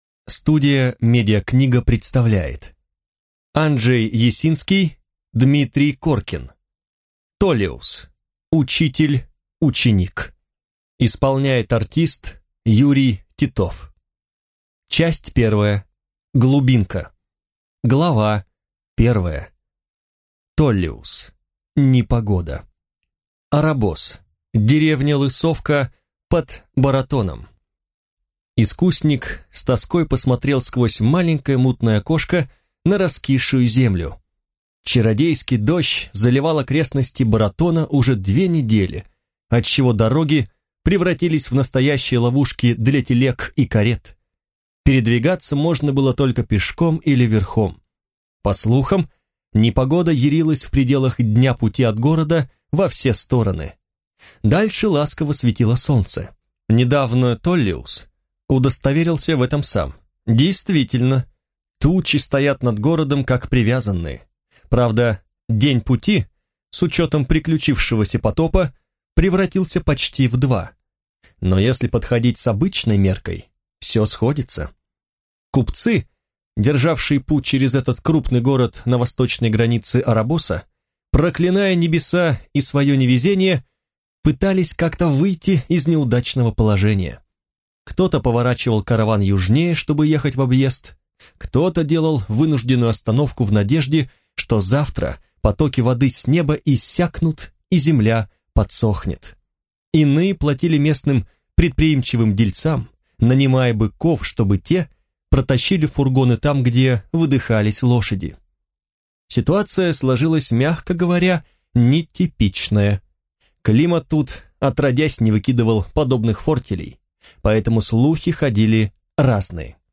Аудиокнига Толлеус. Учитель – ученик | Библиотека аудиокниг